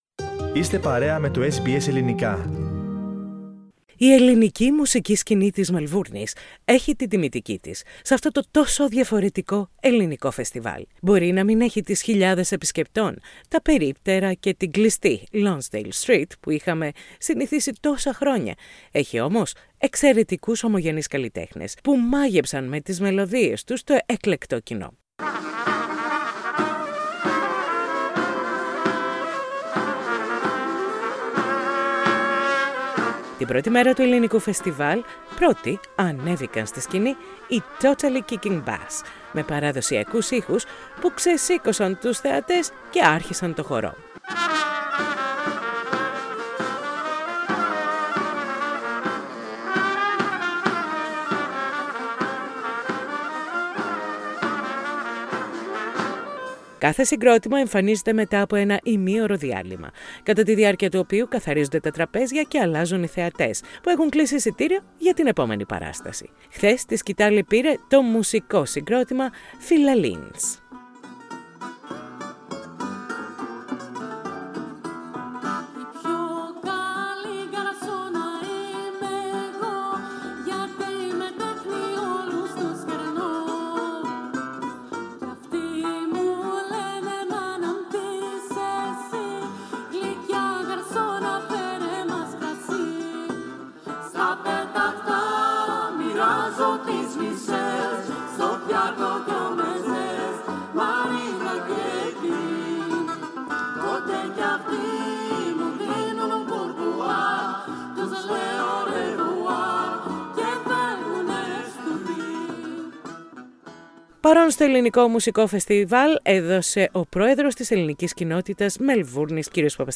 Ελληνικές μελωδίες γεμίζουν το φετινό, μουσικό φεστιβάλ της κοινότητας Μελβούρνης, που είναι πολύ περιορισμένο λόγω των μέτρων κατά της πανδημίας. Σήμερα παρά την βροχή το φεστιβάλ συνεχίζεται κανονικά, σύμφωνα με τους διοργανωτές.